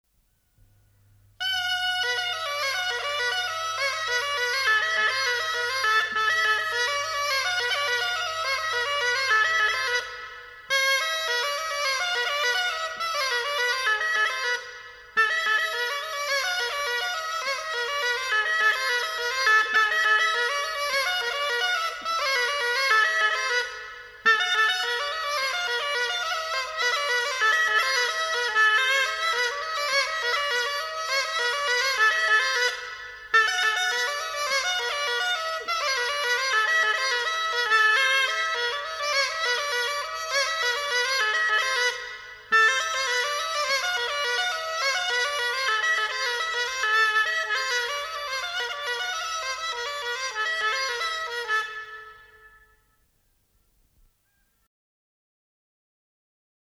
Голоса уходящего века (Курское село Илёк) Заяц (рожок, инструментальный наигрыш)